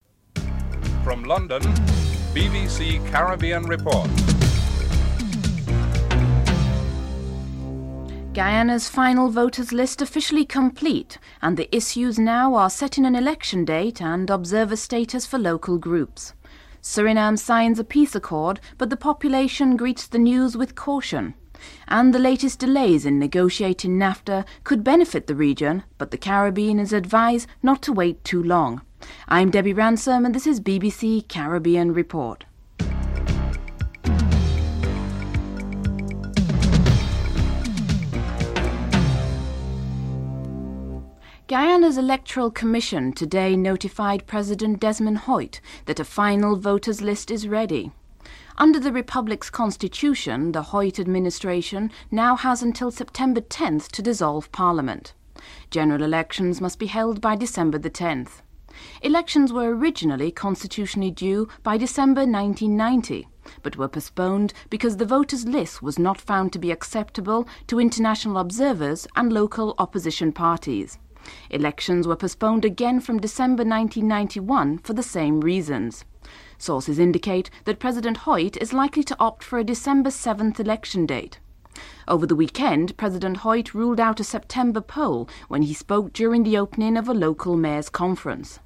1. Headlines (00:00-00:40)